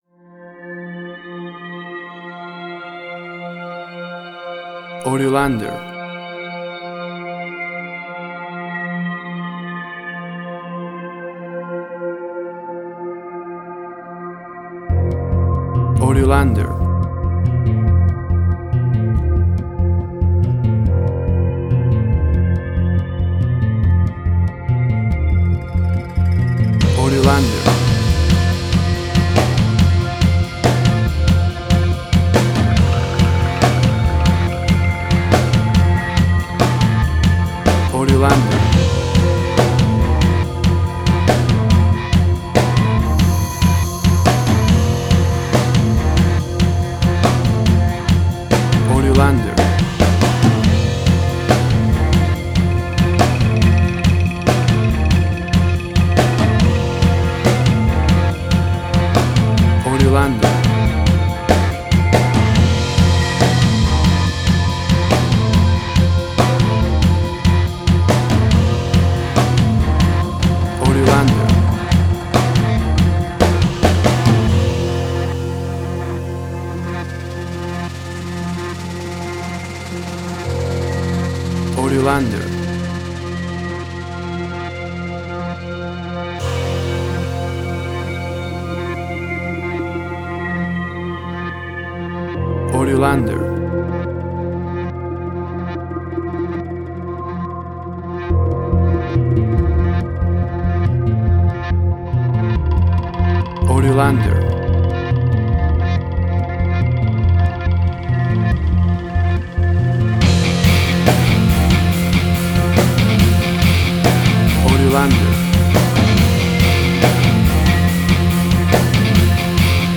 Suspense, Drama, Quirky, Emotional.
Tempo (BPM): 140